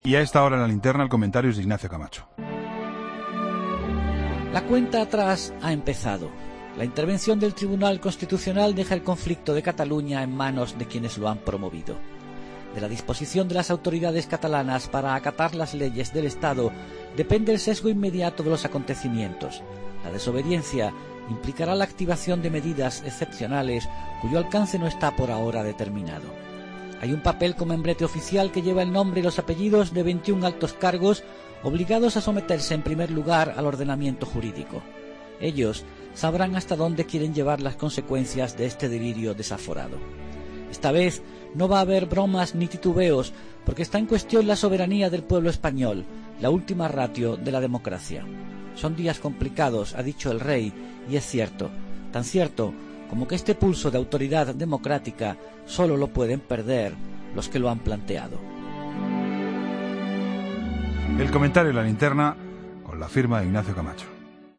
Ignacio Camacho dedica su comentario a la crisis secesionista de Cataluña y a la impugnación de la resolución del Parlament por parte del Tribunal Constitucional.